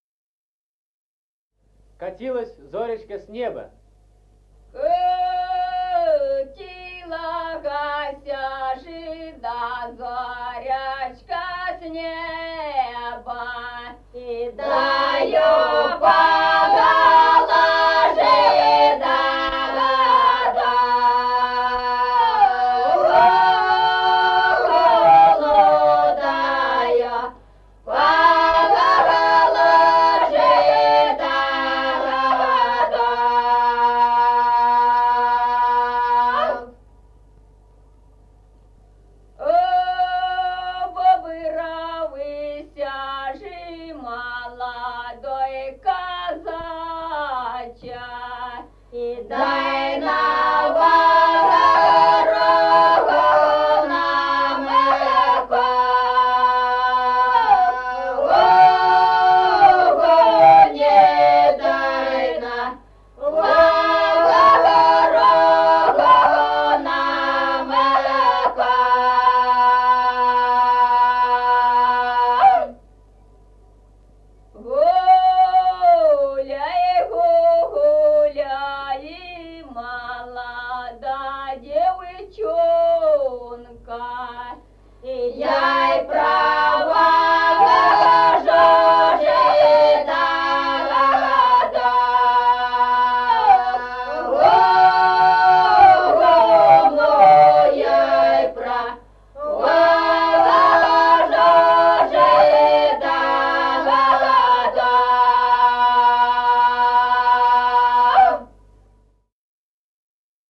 Народные песни Стародубского района «Катилася ж да зоречка», лирическая.
1953 г., с. Мишковка.